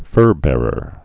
(fûrbârər)